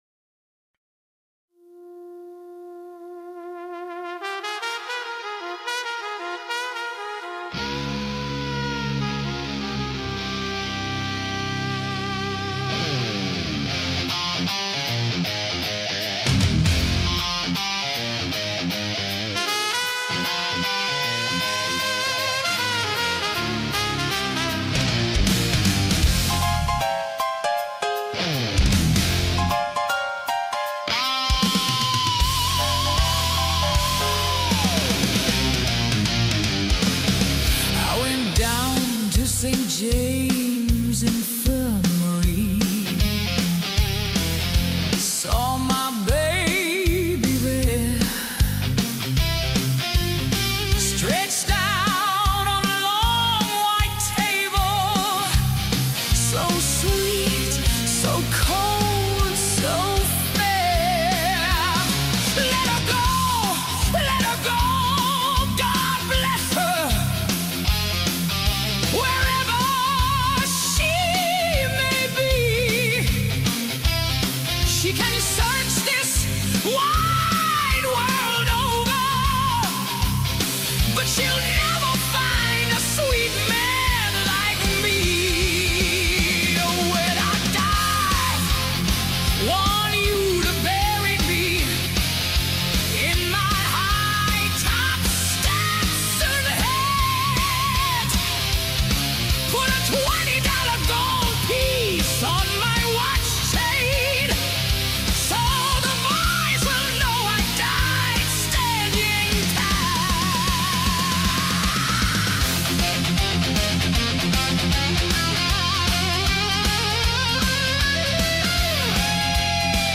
St. James Infirmary The AI Requiem -{Gothic Metal Blues} AI